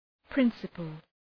Προφορά
{‘prınsəpəl}